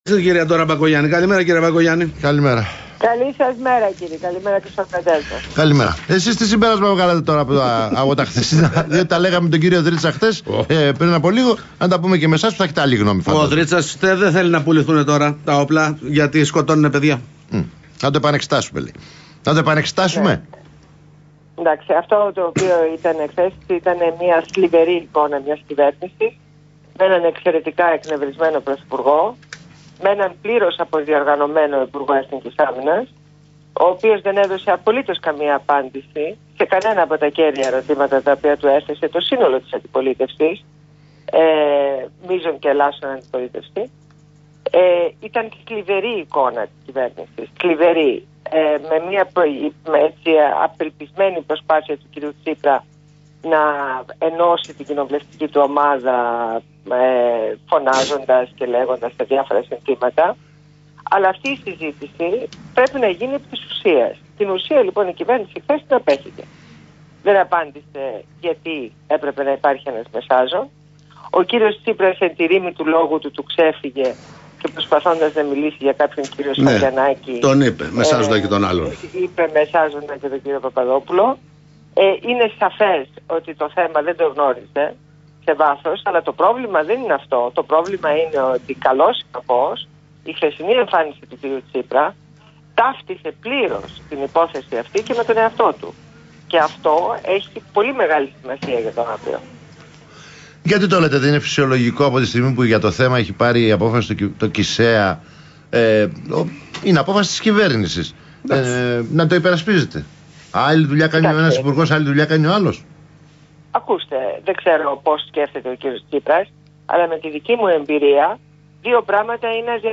Συνέντευξη στο ραδιόφωνο του REALfm